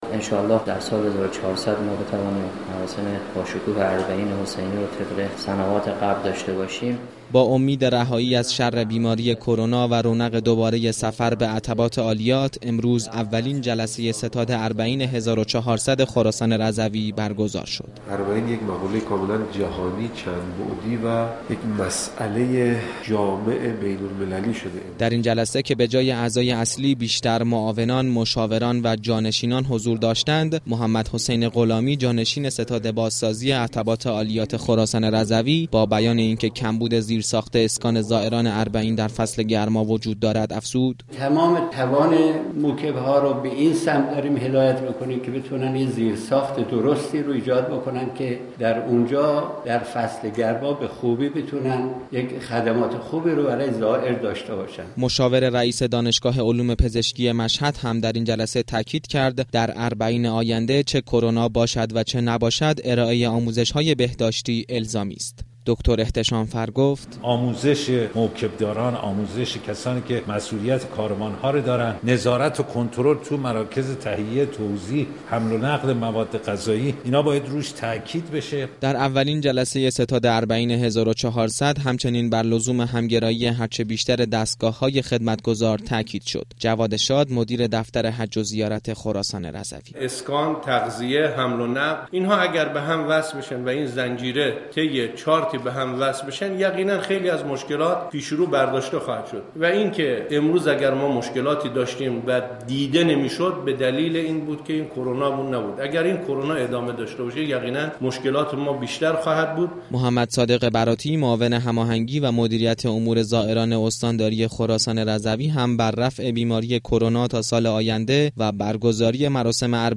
گزارشی خبرنگار رادیو زیارت را از این جلسه بشنوید.